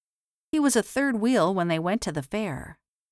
Todos gravados por nativos da língua inglesa.